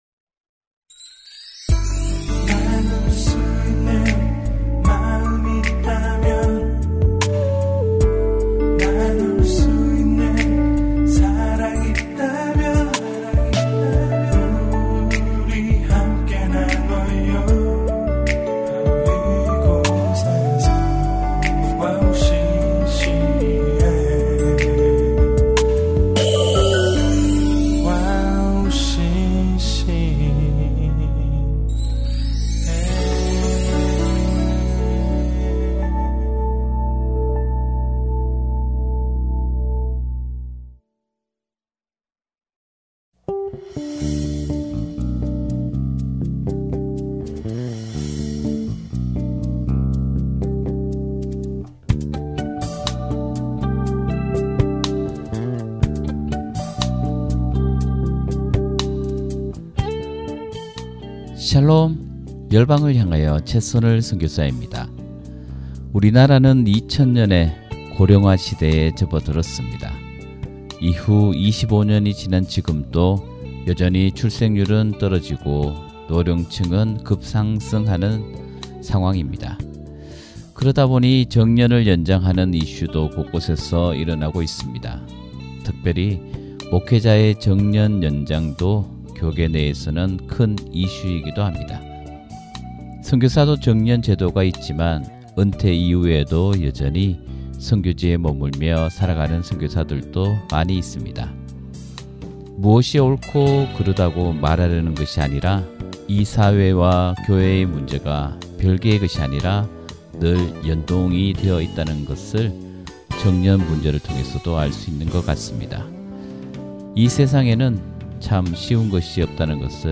기독교 인터넷 라디오 와우씨씨엠에서 제공하는 방송별 다시듣기